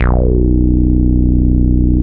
Acid Reso Bass 1 Matrix 12 B1 reso_bass 65 KB
reso_bass.wav